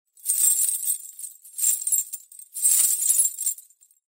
Danza árabe, bailarina mueve las pulseras al bailar 01
agitar
Sonidos: Acciones humanas